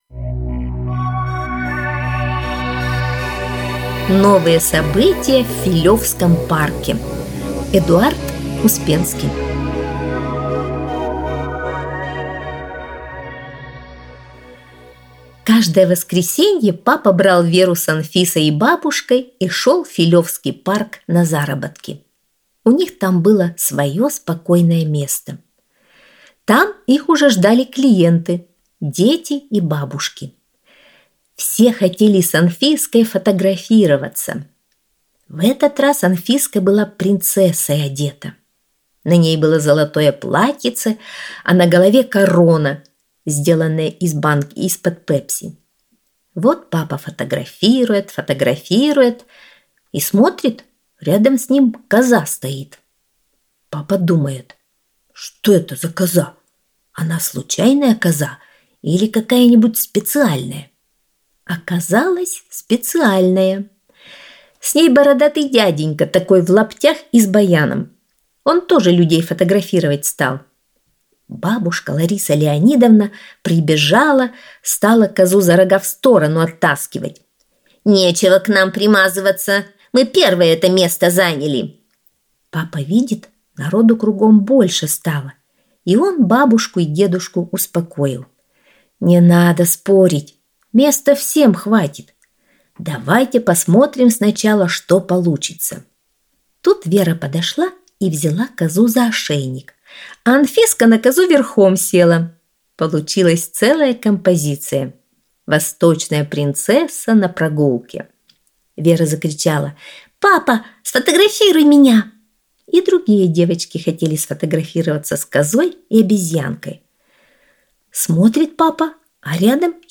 Аудиосказка «Новые события в Филевском парке»